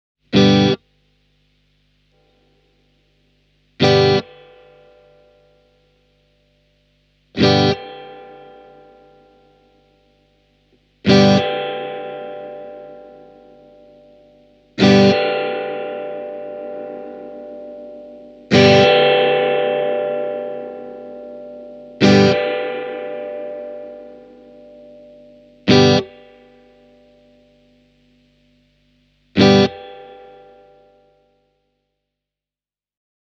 Sinusoidin kaikusoundi ei ehkä ole ihan niin vivahteikas ja tiheä, kuin esimerkiksi Fender Blackface Deluxe Reverb -kombossa. Mutta täytyy muistaa, että tässä on kyse huomattavasti pienemmästä jousitankista, ja siihen nähden Gurus Sinusoidin tarjoama jousikaiku kuulostaa jopa hämmästyttävän hienolta:
gurus-amps-sinusoid-e28093-spring-reverb.mp3